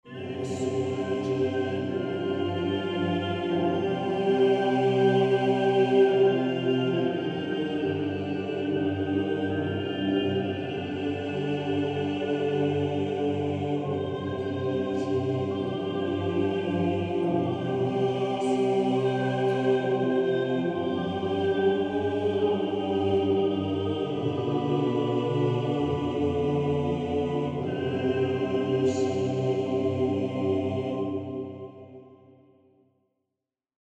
classique - orchestre - opera - vieux - chef